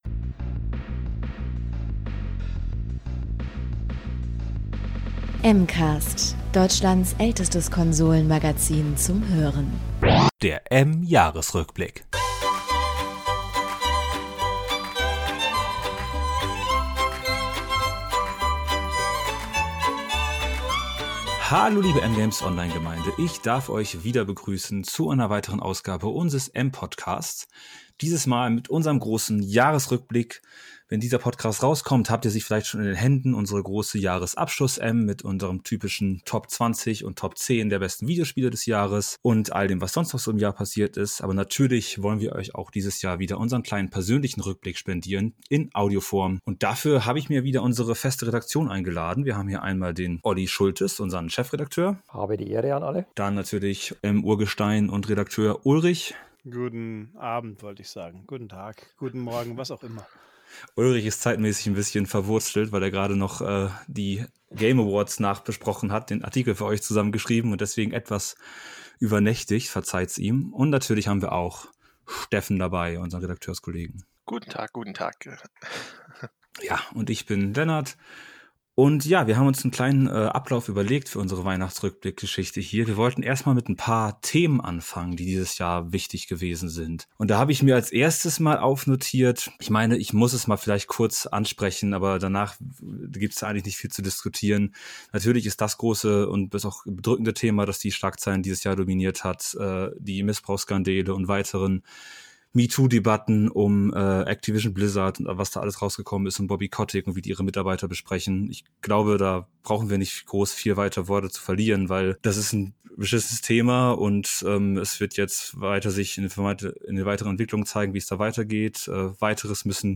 Und wer lieber nicht per Standbild-Video läuschen möchte: Eine MP3-Fassung unserer Plauderei könnt Ihr hier herunterladen.